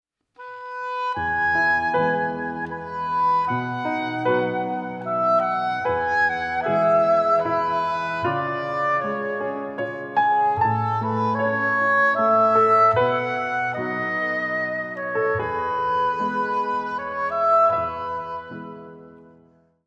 a sultry and suspenseful score